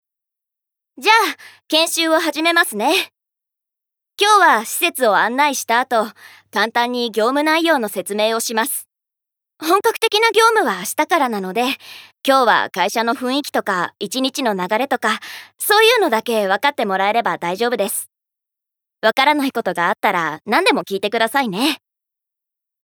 ボイスサンプル
セリフ２